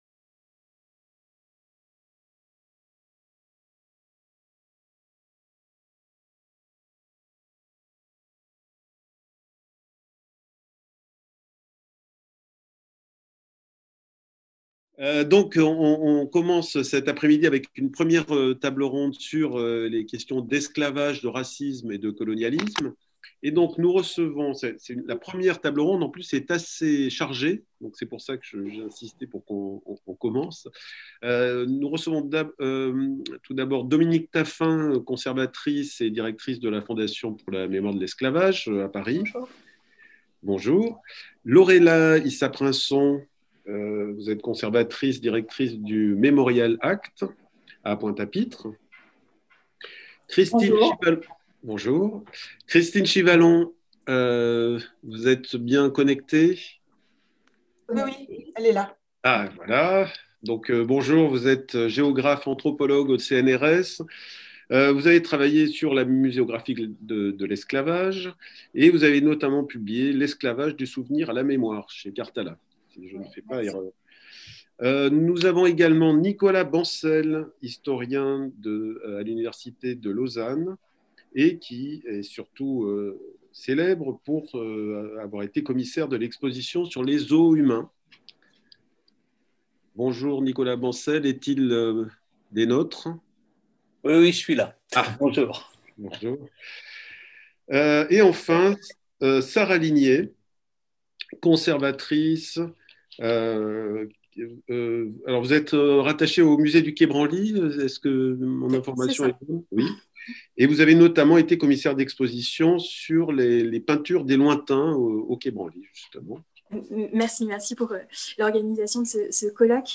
Colloque | 6 mai Le Musée national de l’histoire de l’immigration et la Plateforme internationale sur le Racisme et l'Antisémitisme PIRA (FMSH,EPHE) vous proposent un colloque international autour des traitements du racisme et de l'antisémitisme dans les expositions muséales.